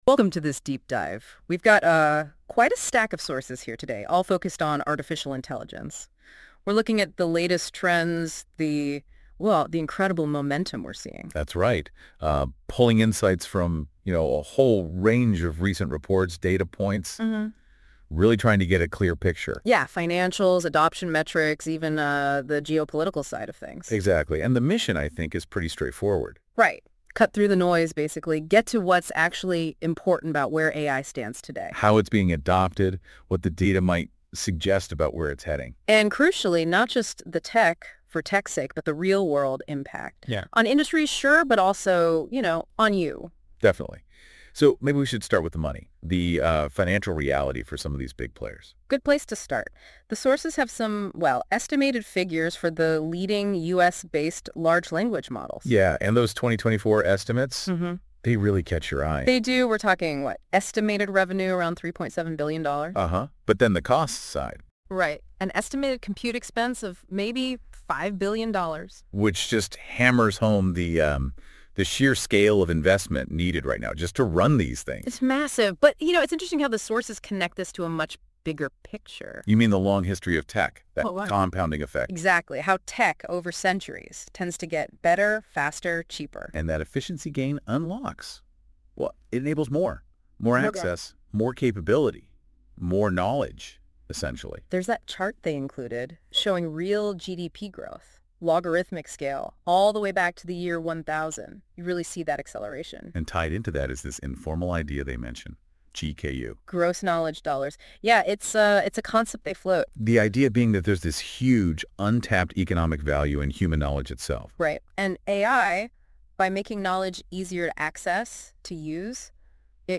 AI Audio Overview generated using Notebook LLM on BondCap Report